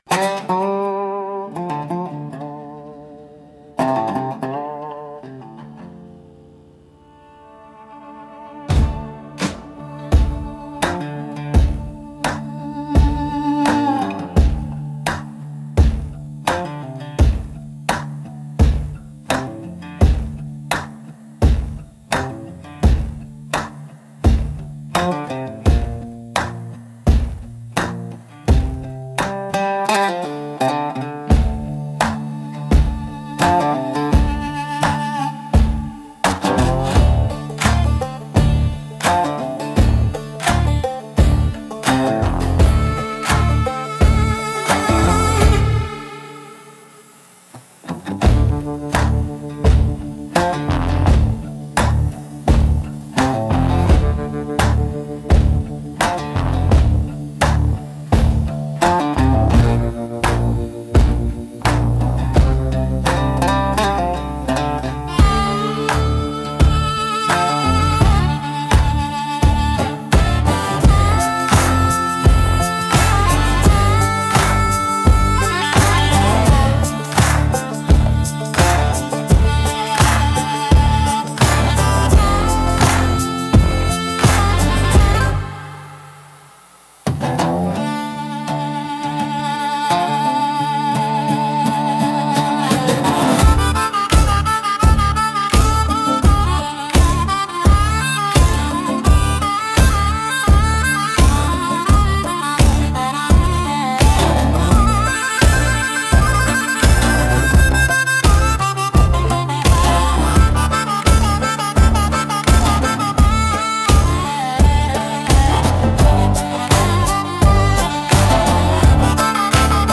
Instrumental - Hi-Hats 2.29